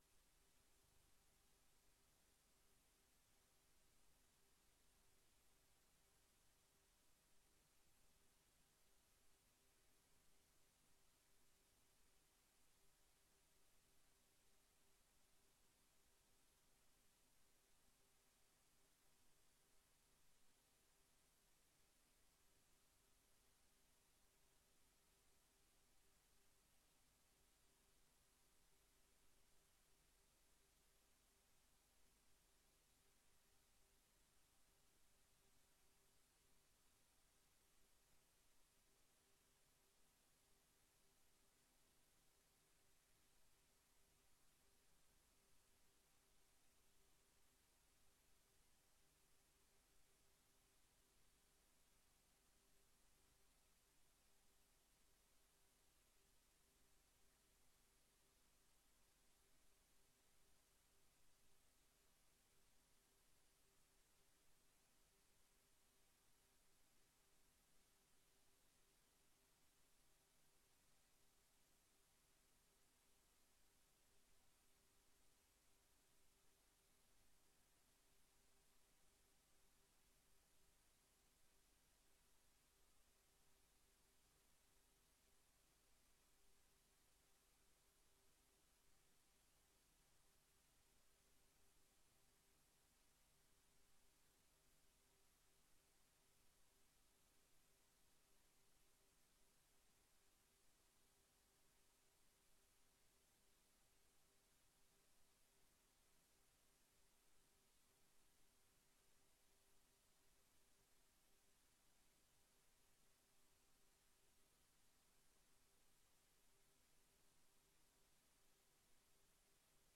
Raadsvergadering 08 mei 2025 20:00:00, Gemeente Leusden